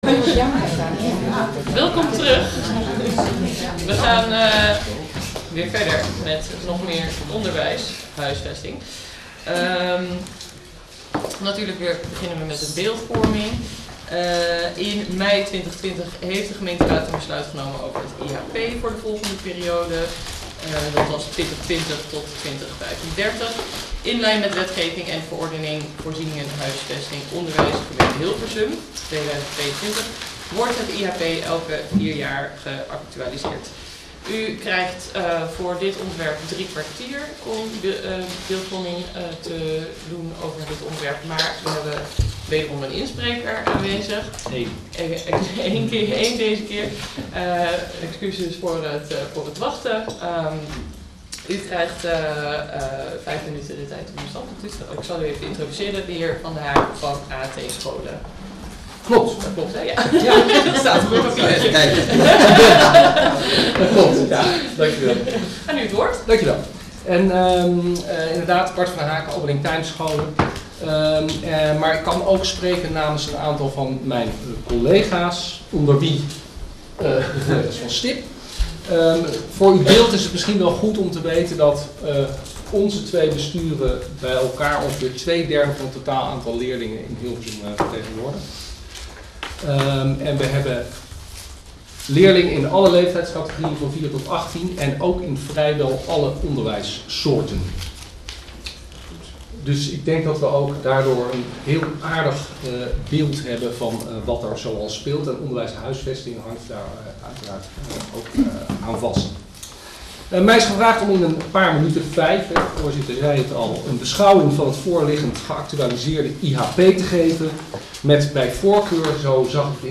Agenda Hilversum - COMMISSIE Zorg, Welzijn en Samenleving woensdag 20 maart 2024 20:00 - 22:30 - iBabs Publieksportaal